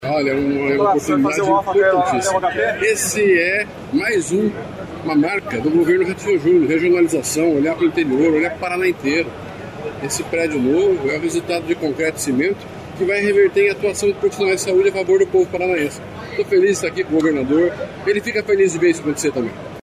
Sonora do secretário da Saúde, Beto Preto, sobre a ampliação do pronto-socorro do Honpar, em Arapongas
SECR. BETO PRETO - ENTREV.mp3